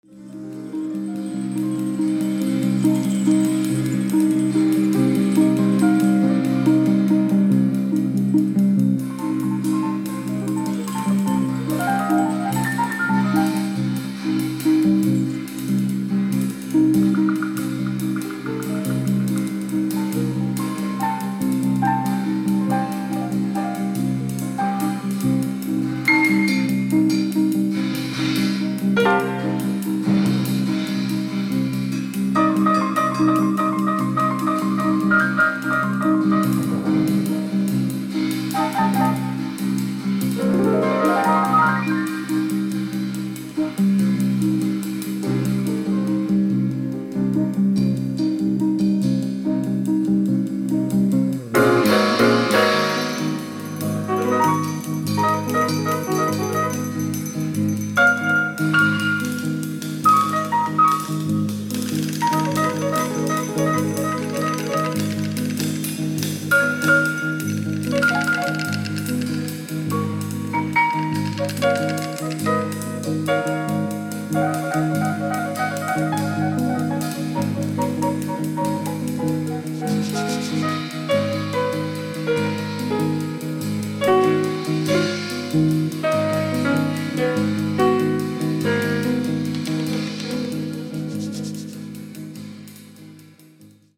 Drums
Electric Bass
Percussion
Piano, Electric Piano